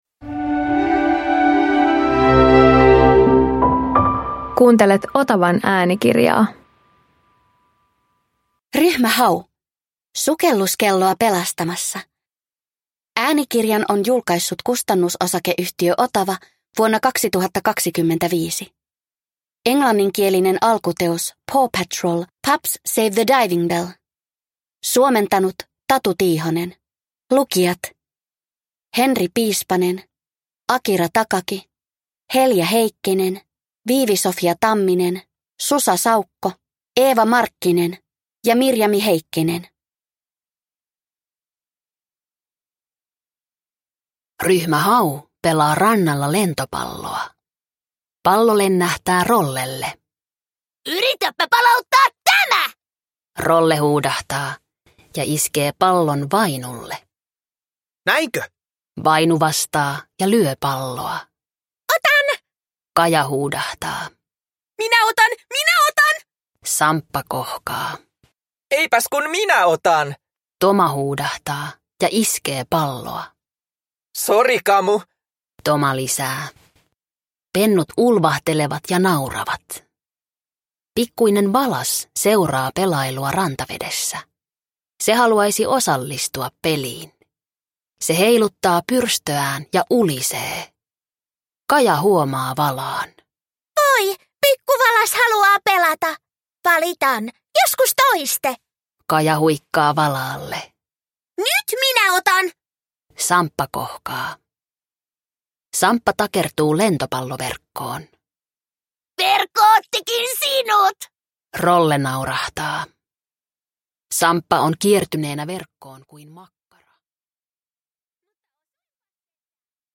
Ryhmä Hau - Sukelluskelloa pelastamassa – Ljudbok